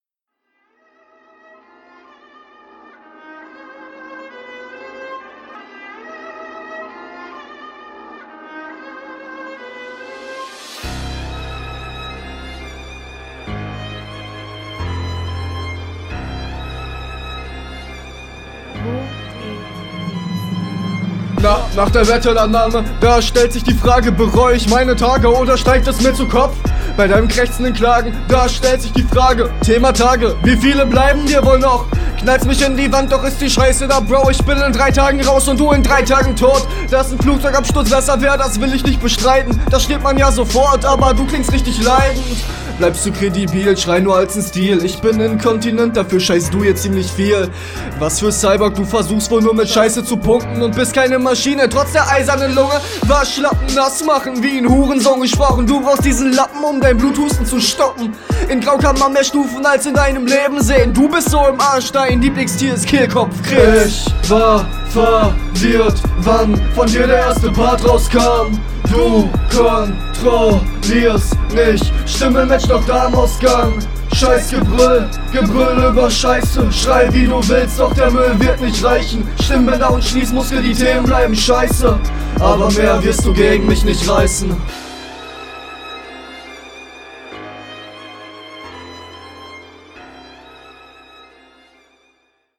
Flow: Flowlich auch ganz solide, vom Stimmeinsatz finde ich dein Gegner hier etwas überlegen.
Beim Flow nehmt ihr euch nicht viel.